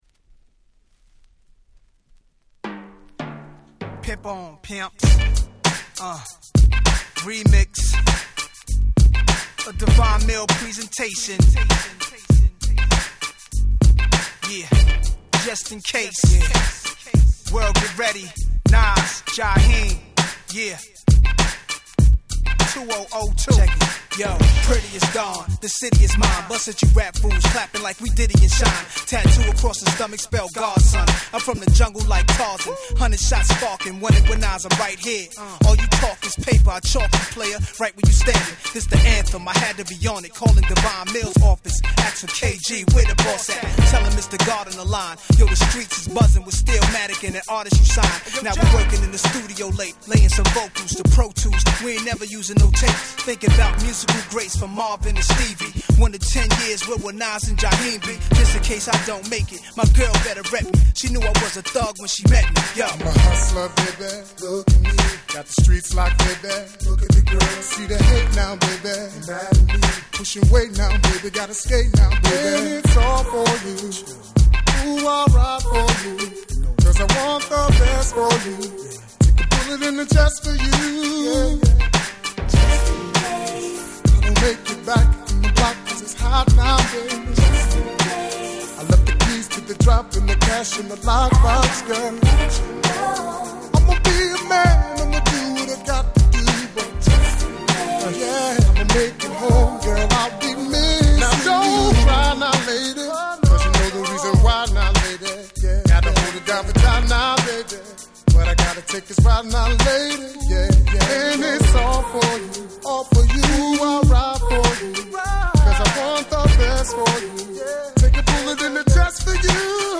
【Media】Vinyl 12'' Single (Promo)